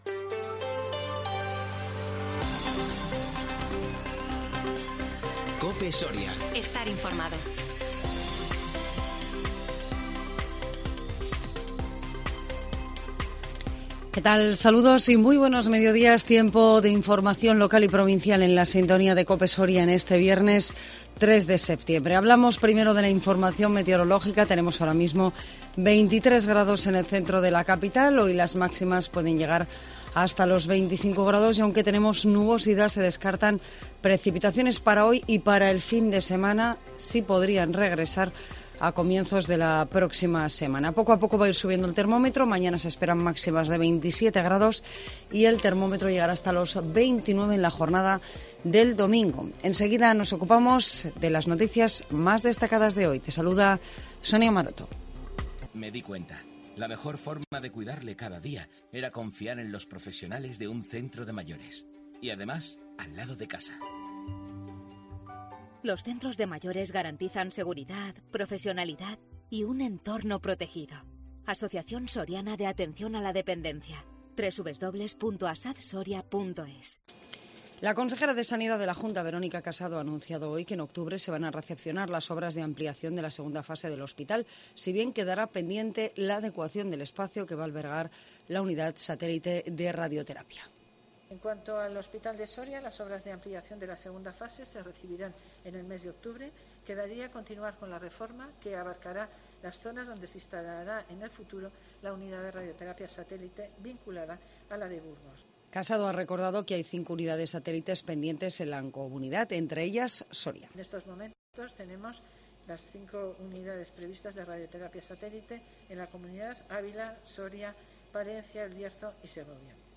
INFORMATIVO MEDIODÍA 3 SEPTIEMBRE 2021